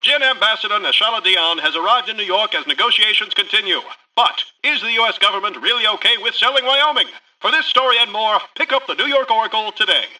Newscaster_headline_51.mp3